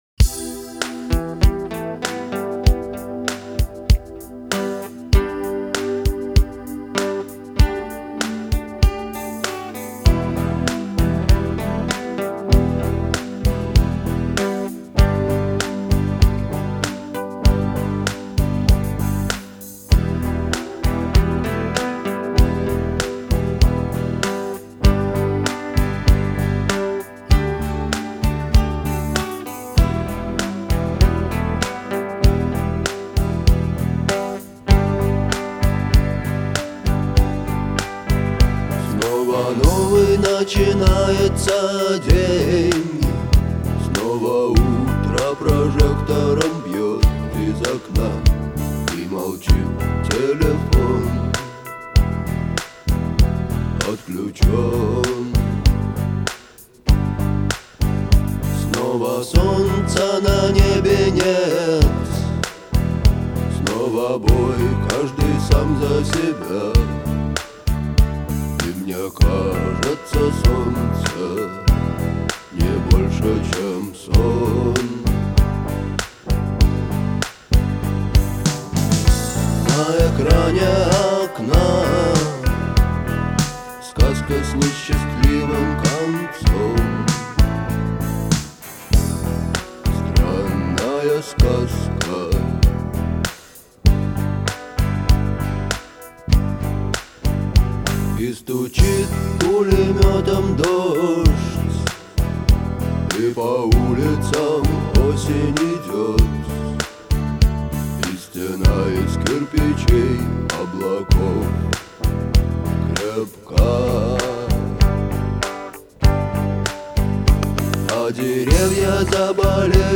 мелодичным гитарным рифом